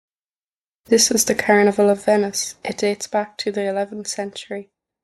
A narrator briefly introduces several famous festivals from history, sharing when and where they took place.